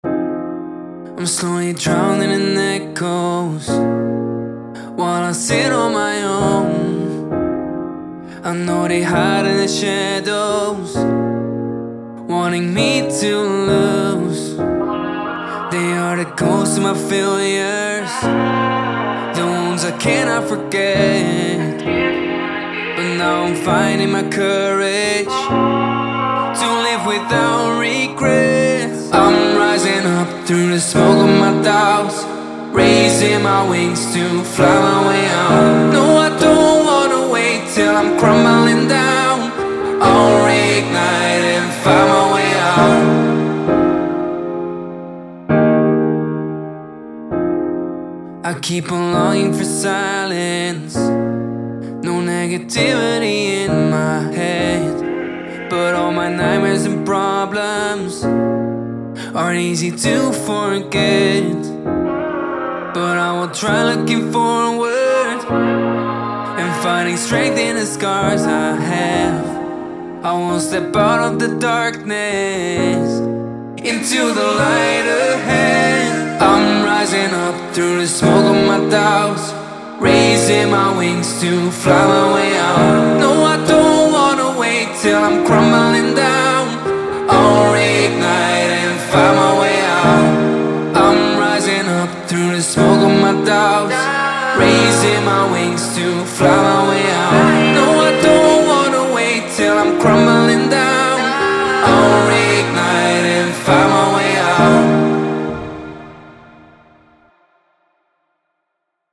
132 Bpm – Fminor